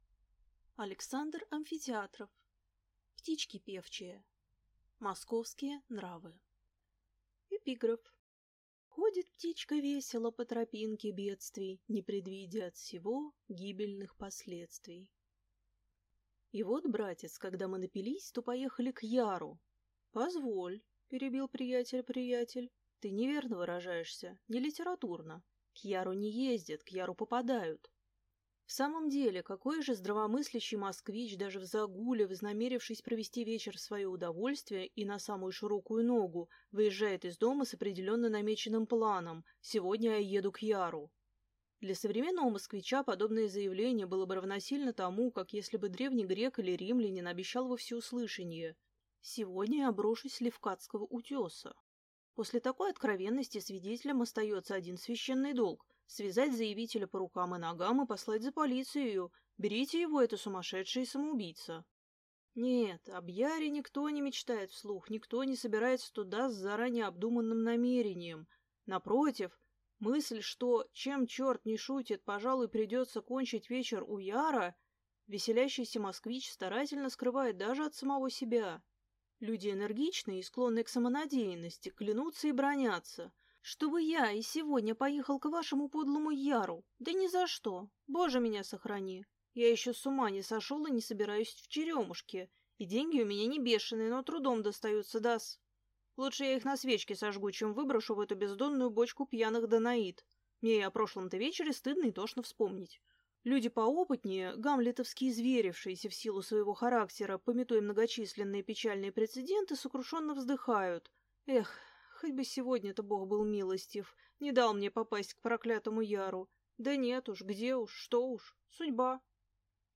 Aудиокнига Птички певчие